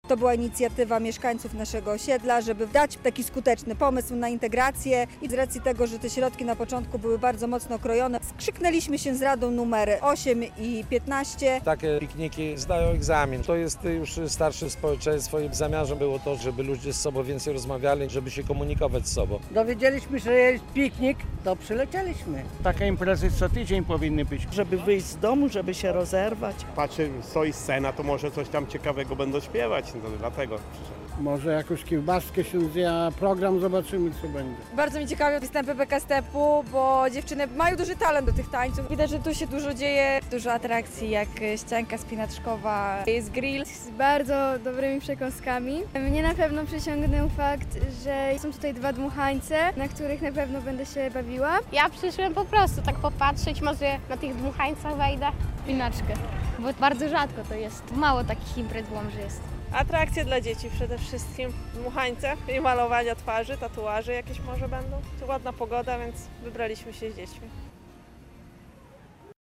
Piknik sąsiedzki w Łomży - relacja